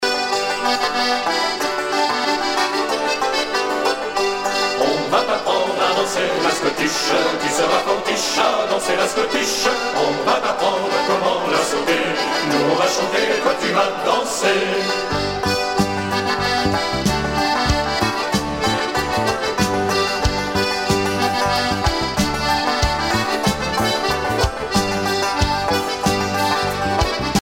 danse : scottich trois pas
Pièce musicale éditée